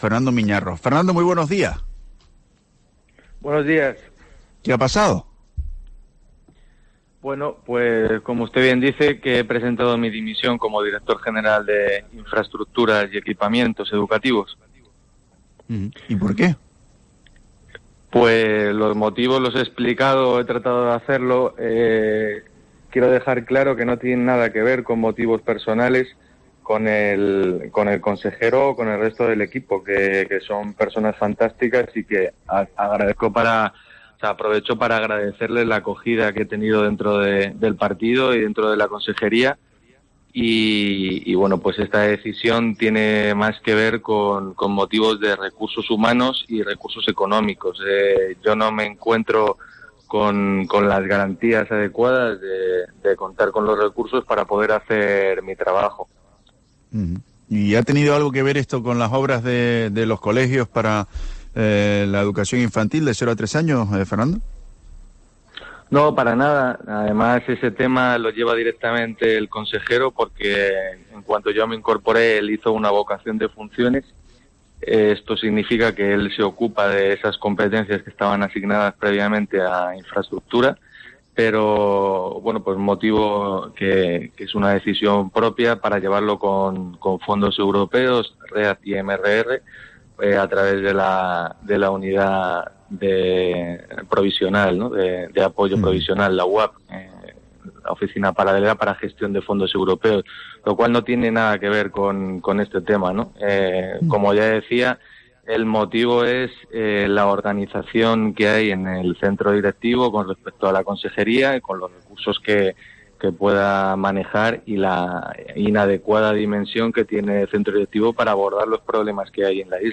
En una entrevista en Herrera en COPE Canarias, Fernando Miñarro ha asegurado que no cuenta con suficientes "recursos humanos ni económicos".